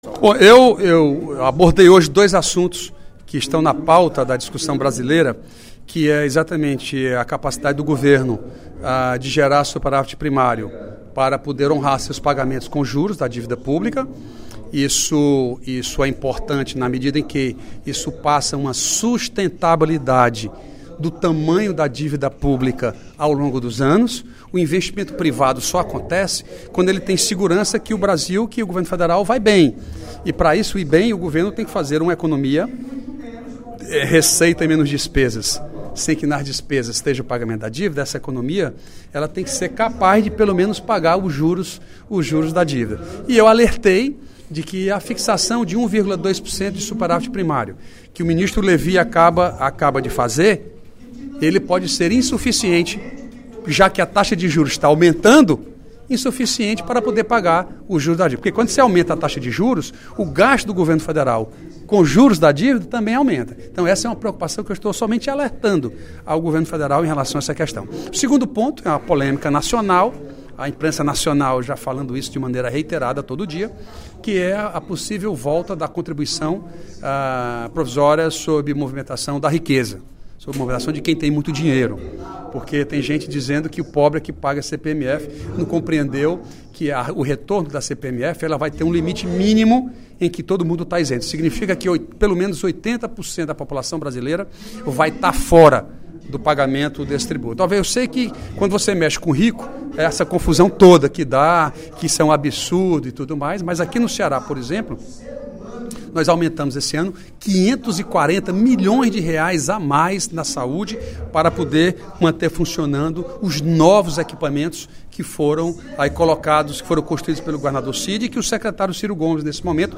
No primeiro expediente da sessão plenária desta quinta-feira (04/12), o deputado Mauro Filho (Pros) fez uma avaliação  sobre o projeto da alteração na meta do superávit primário pelo Governo Federal e esclareceu sobre a possível recriação da Contribuição Provisória sobre Movimentação Financeira (CPMF).